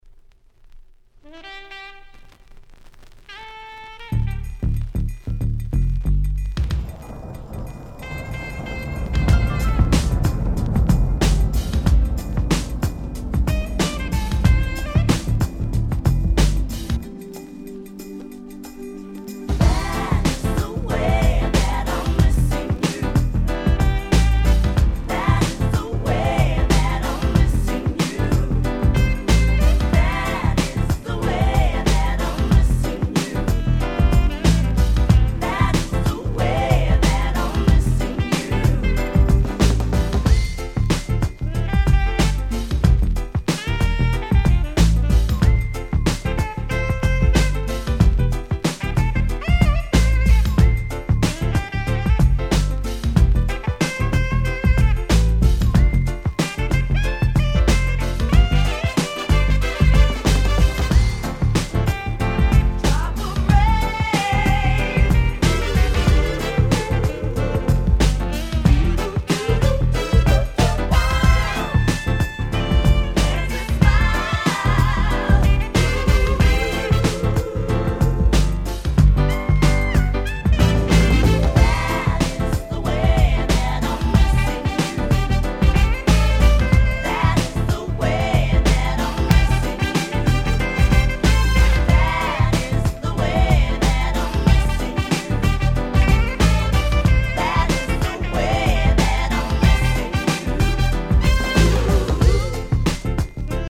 UK R&B Classic !!